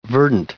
1032_verdant.ogg